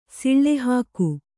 ♪ siḷḷe hāku